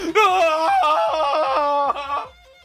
hituji_danmatsuma
hituji_danmatsuma.mp3